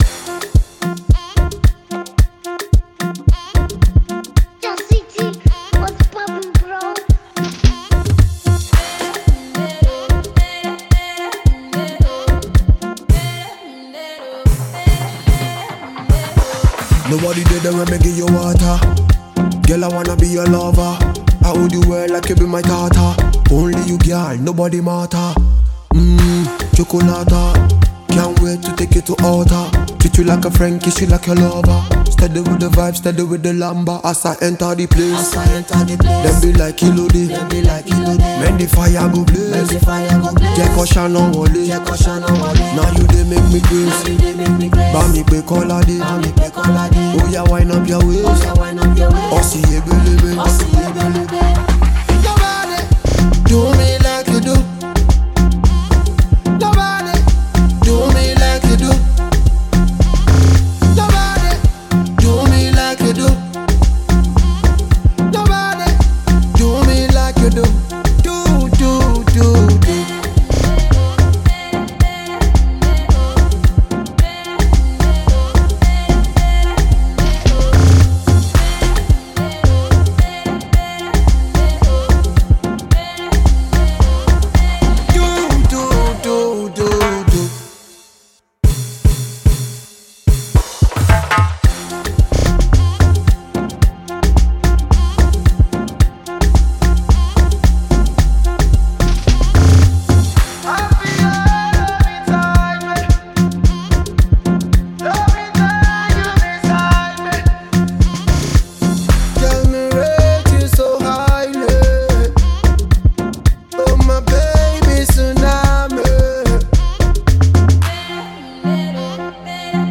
Award-winning talented Dancehall and afrobeats singer